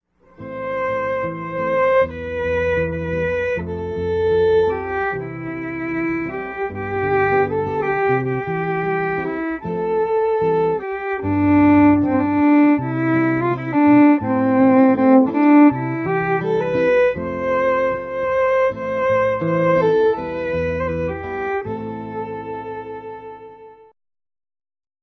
guitarist
a collection of four traditional Irish tunes